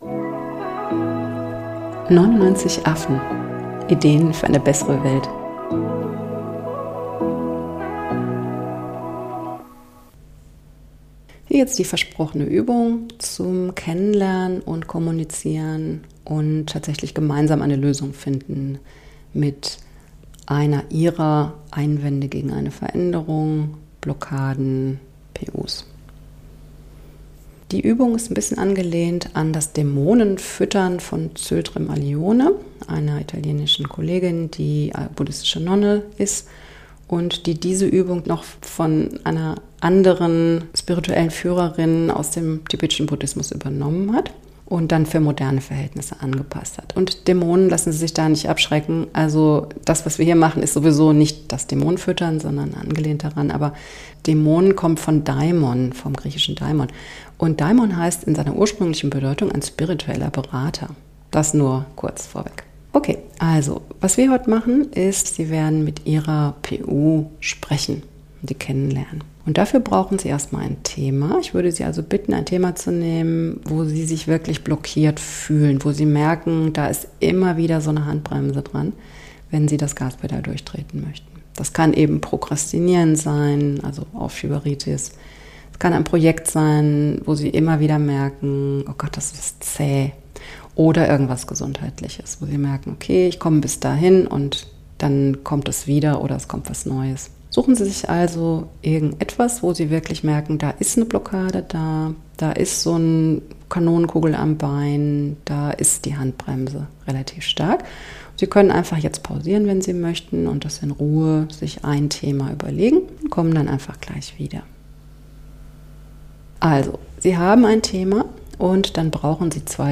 Hier im zweiten Teil des Podcastes zu Blockaden führe ich Sie durch eine Übung, mit deren Hilfe Sie Ihre Blockade verstehen, mit ihr reden und eine gemeinsame Lösung finden können.